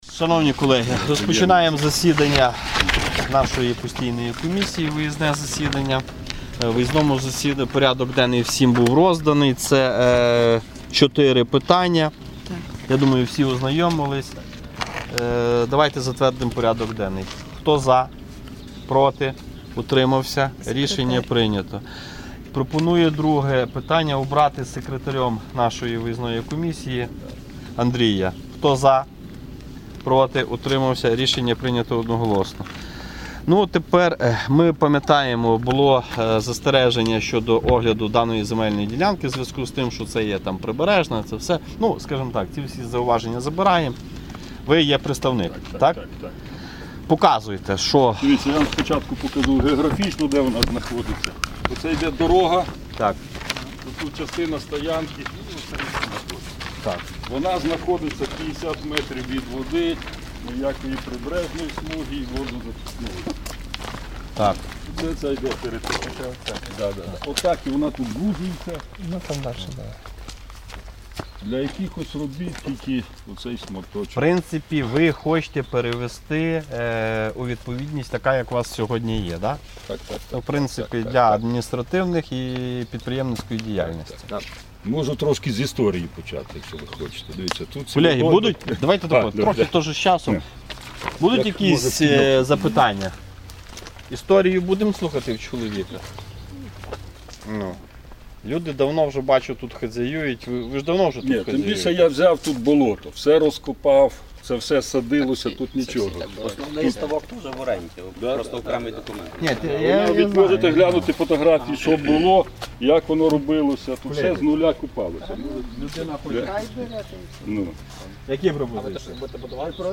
Постійна комісія 05.07.2023 (виїздне засідання)